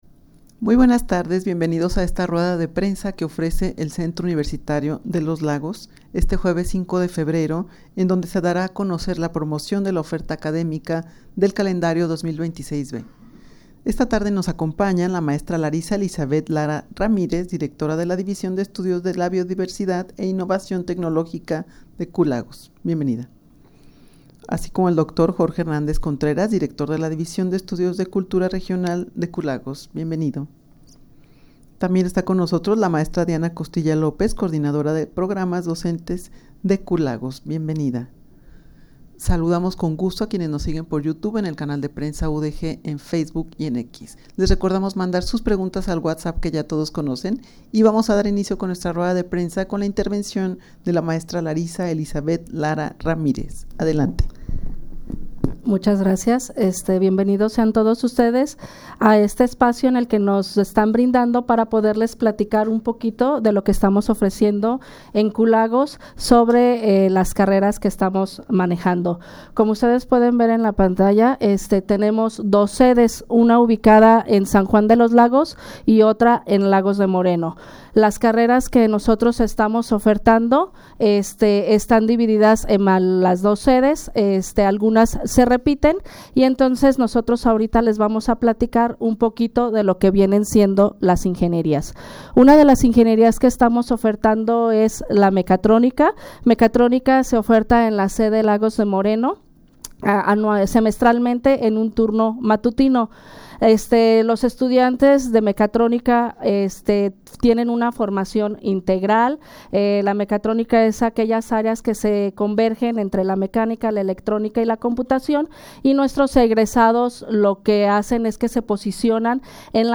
Audio de la Rueda de Prensa
rueda-de-prensa-promocion-de-la-oferta-academica-2026-b-del-culagos.mp3